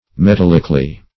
metallicly - definition of metallicly - synonyms, pronunciation, spelling from Free Dictionary Search Result for " metallicly" : The Collaborative International Dictionary of English v.0.48: Metallicly \Me*tal"lic*ly\, adv. In a metallic manner; by metallic means.